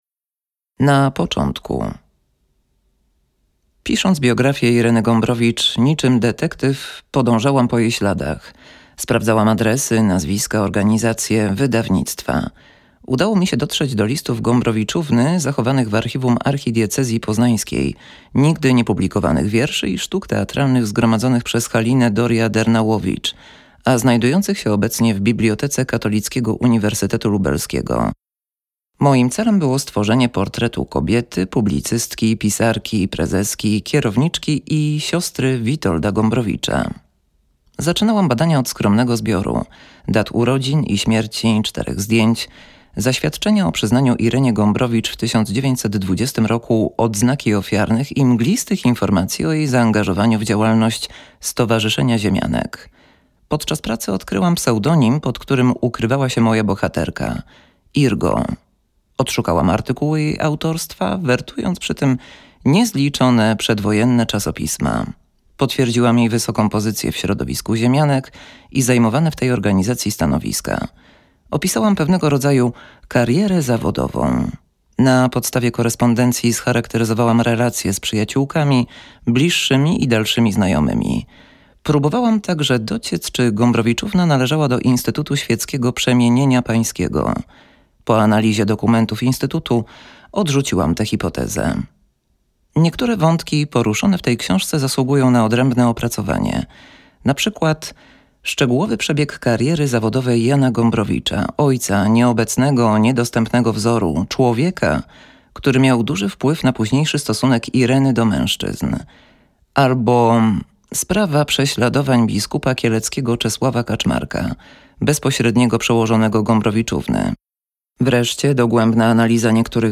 Audiobook + książka Starsza siostra.